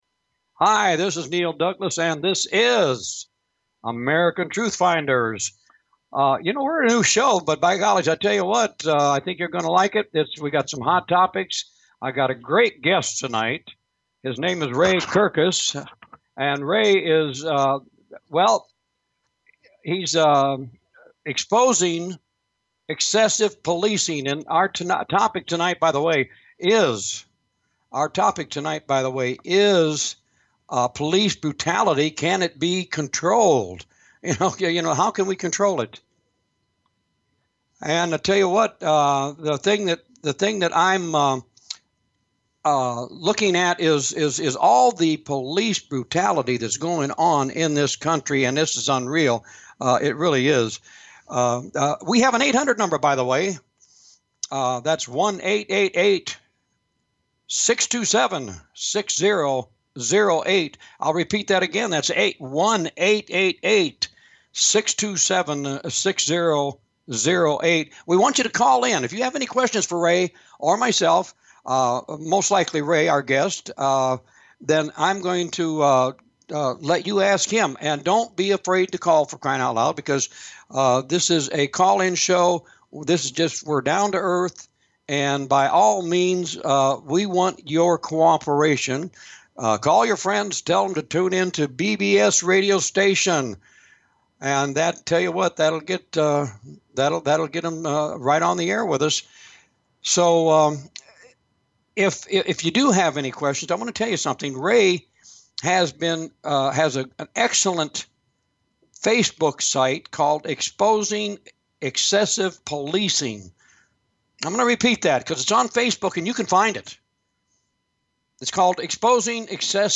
Talk Show Episode, Audio Podcast, Police Brutality in America!